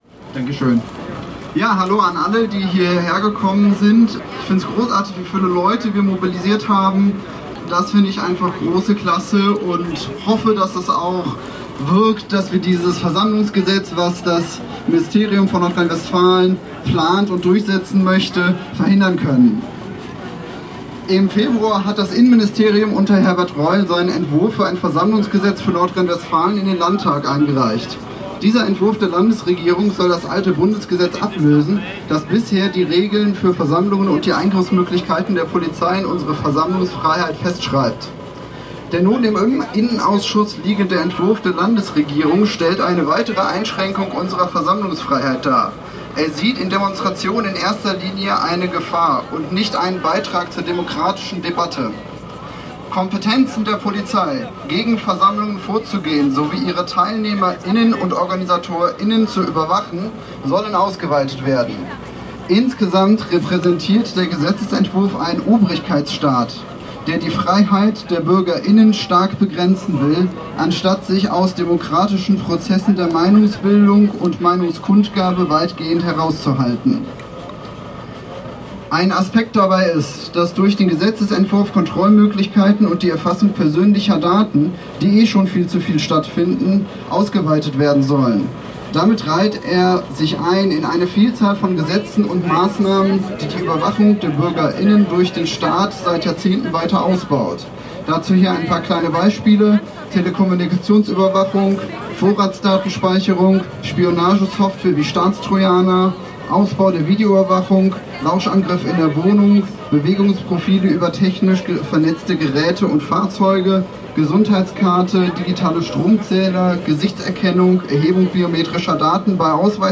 Abschlusskundgebung
Am Zielpunkt angekommen, lauschten die Demonstrierenden den Abschlussreden.
Redebeitrag der Kampagne „Kameras stoppen!“[60, 61]
Der Redner sprach zu folgenden Themen: